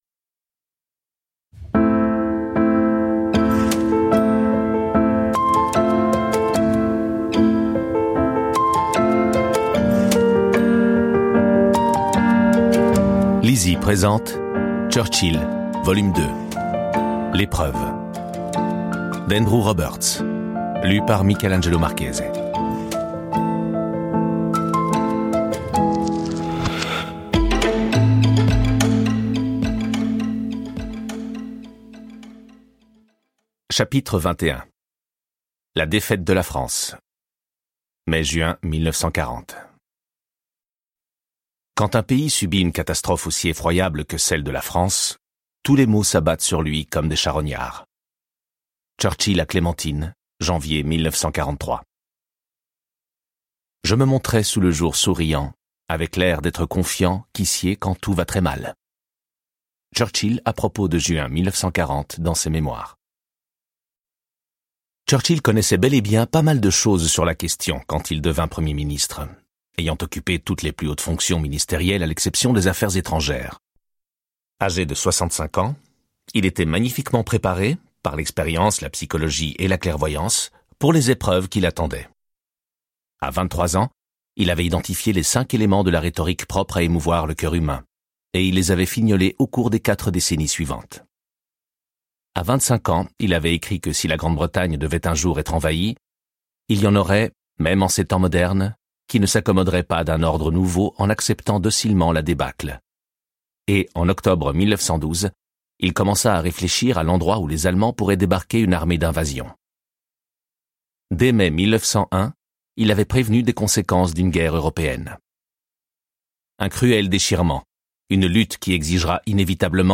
Vous trouverez dans ce livre audio la seconde partie de la biographie de Churchill, intitulée " L'épreuve".